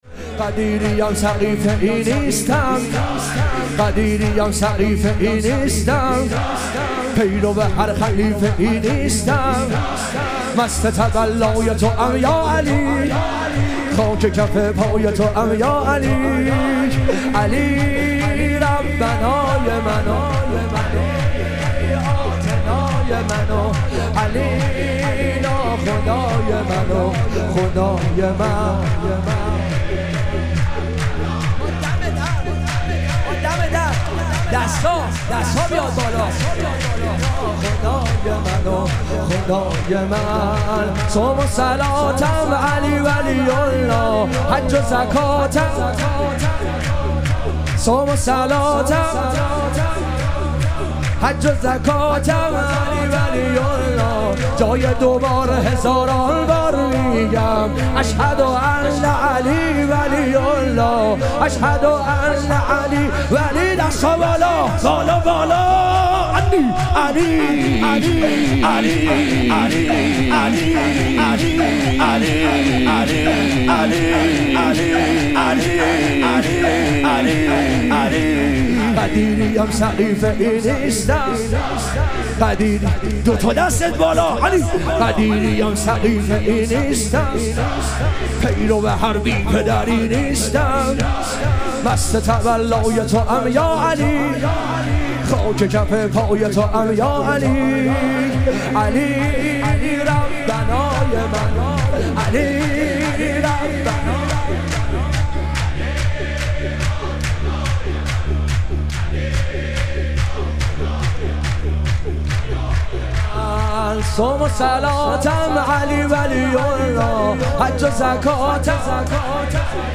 ظهوروجود مقدس امام هادی علیه السلام - شور